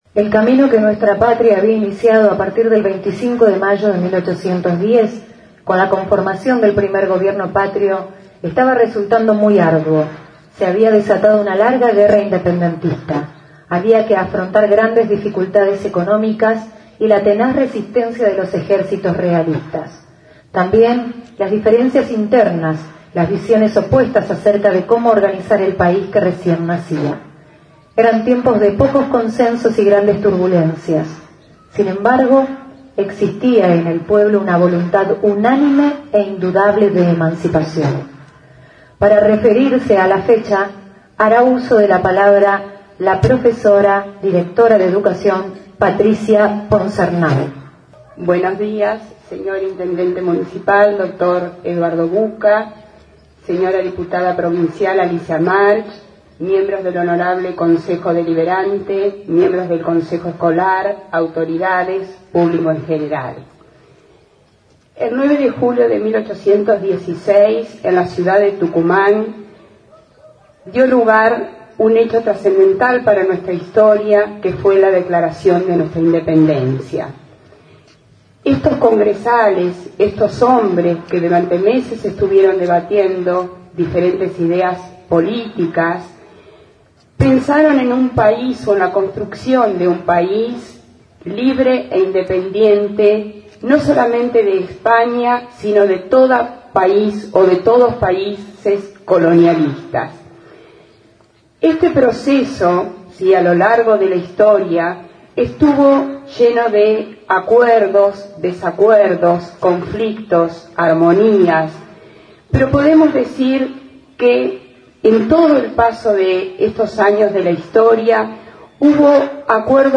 Palabras Alusivas y Todas las Imágenes del Acto y el Desfile